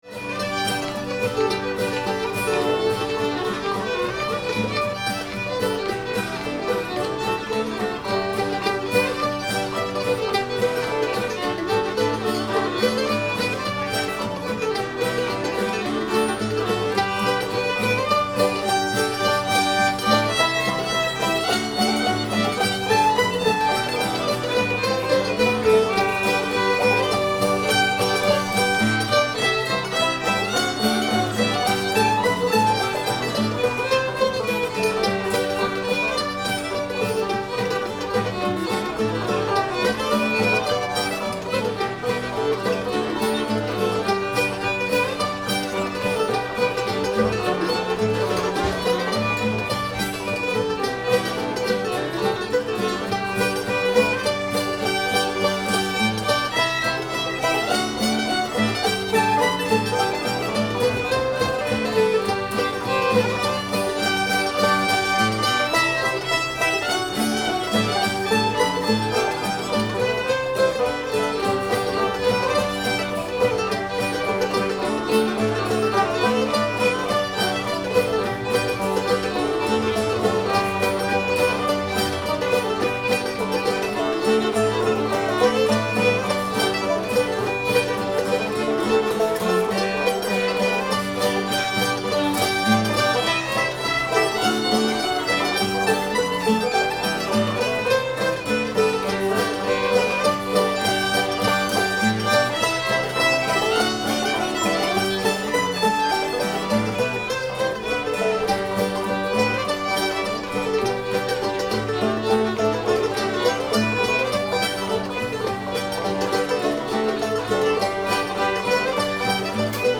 magpie [G]